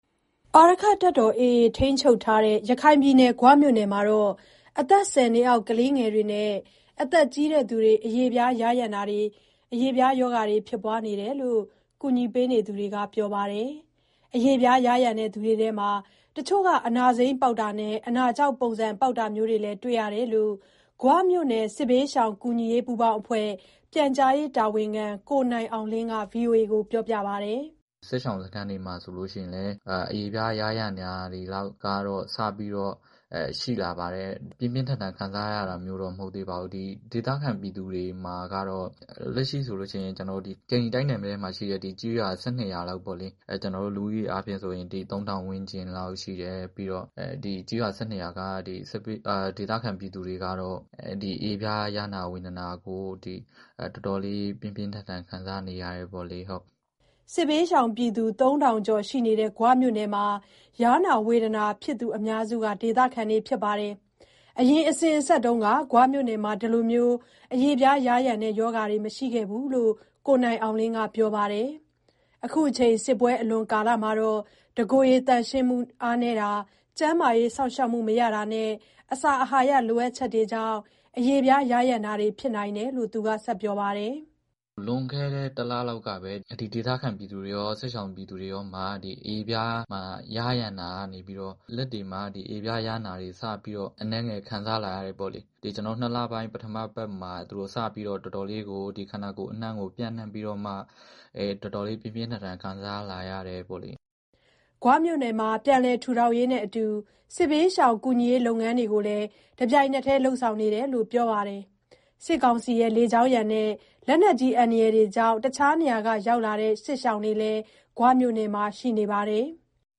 စစ်ဒဏ်သင့်ရခိုင်ပြည်နယ်က ကျန်းမာရေးပြဿနာ ရန်ကုန်က သတင်းပေးပို့ထားတာကို အခြေခံပြီး တင်ပြထားပါတယ်။